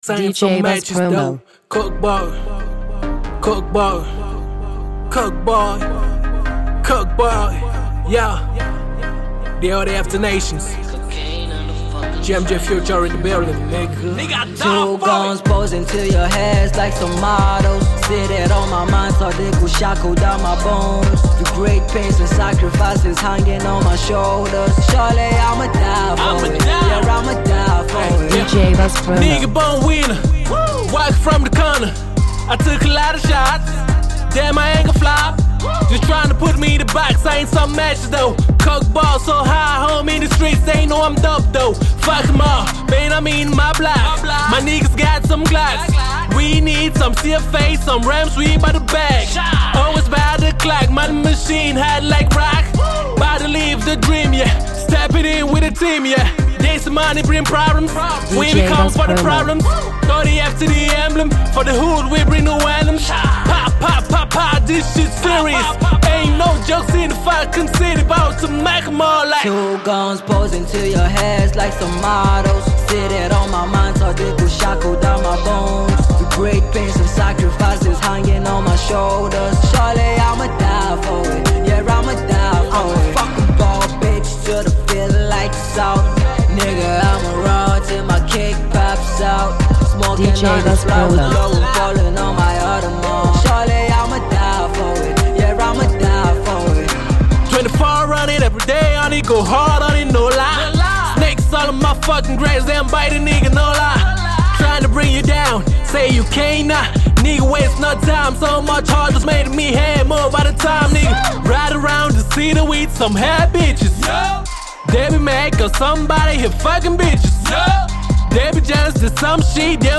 Original Mix